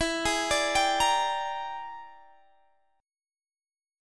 E7#9b5 Chord
Listen to E7#9b5 strummed